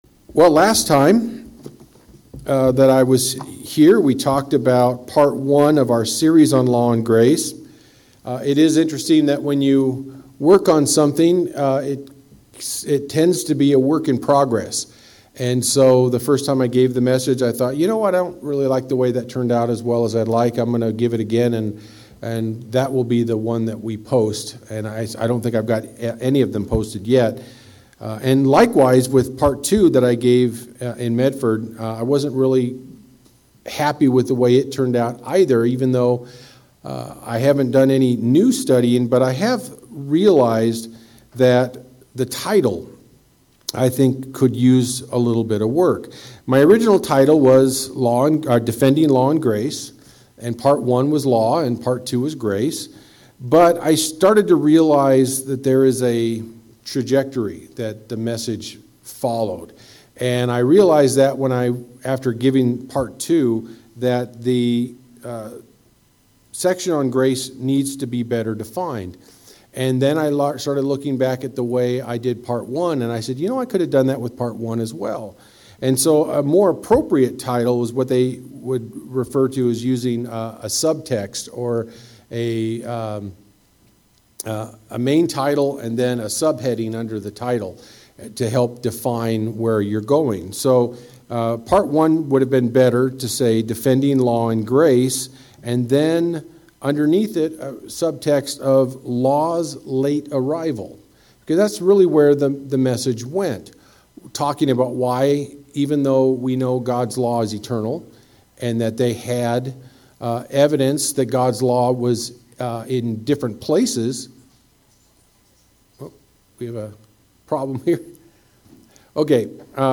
How is grace applied to our lives when we continue to stumble in life? This sermon will discuss how God intends for grace to work in the lives of imperfect people.